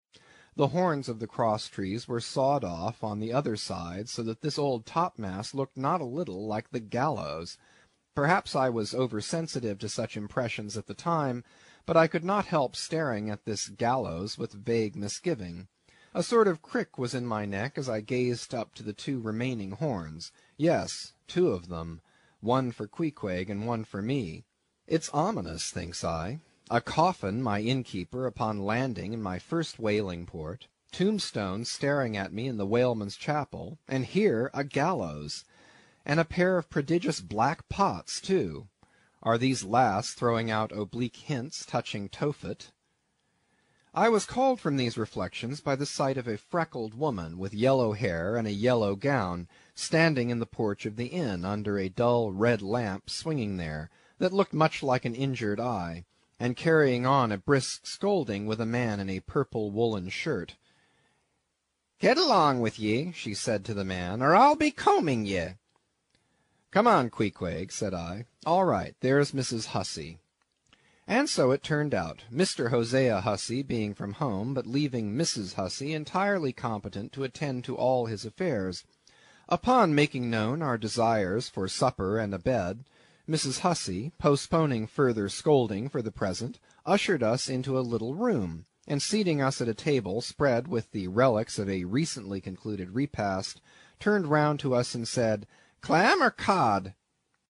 英语听书《白鲸记》第53期 听力文件下载—在线英语听力室